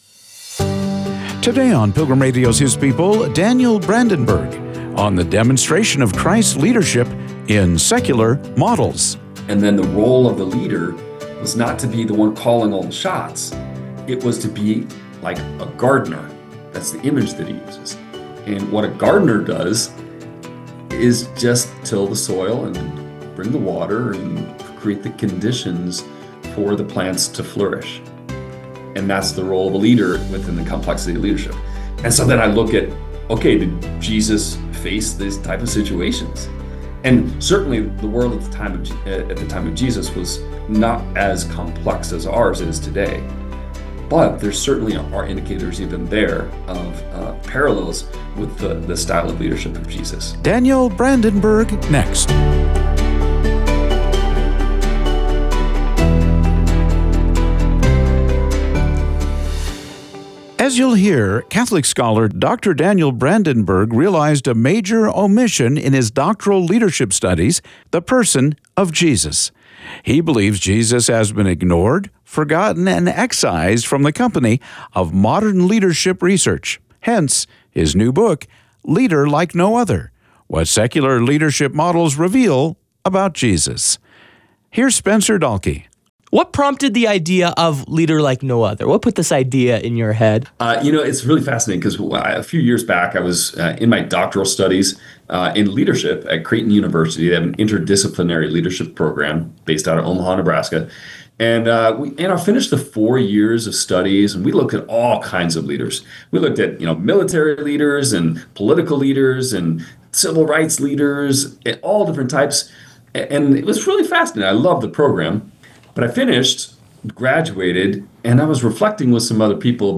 His People interviews by Pilgrim Radio